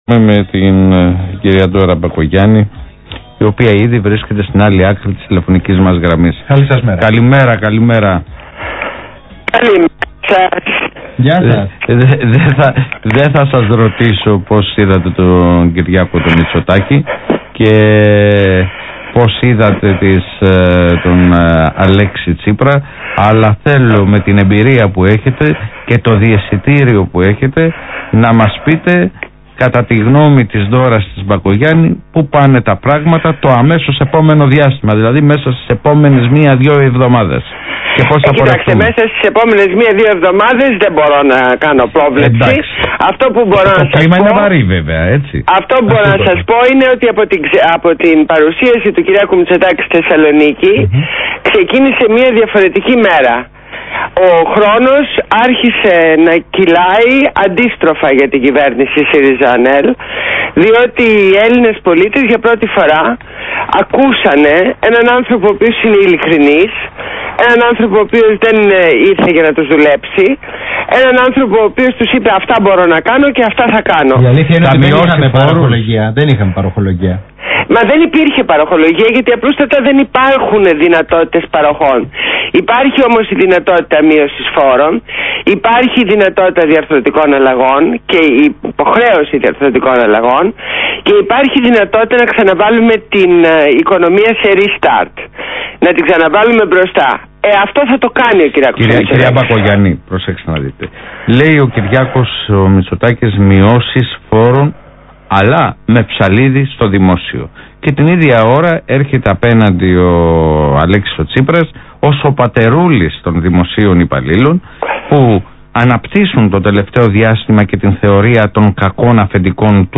Συνέντευξη στο ραδιόφωνο του ALPHA 98,9